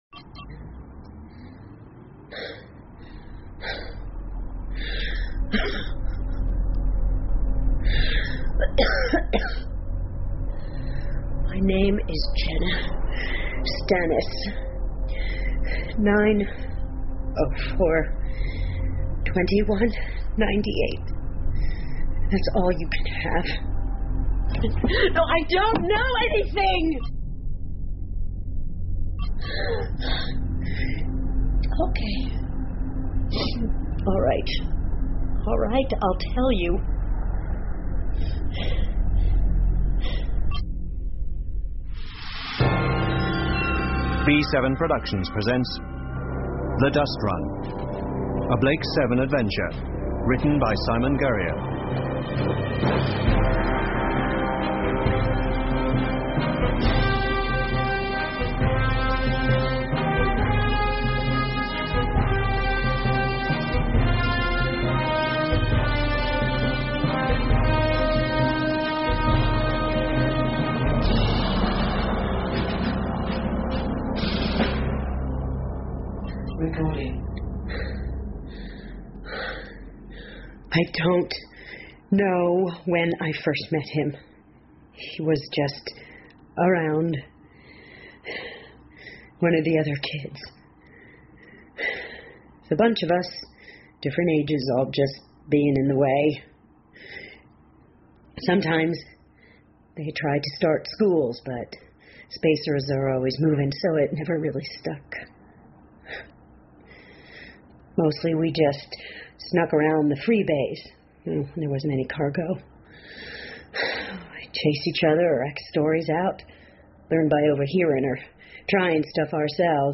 英文广播剧在线听 Benedict Cumberbatch 06 听力文件下载—在线英语听力室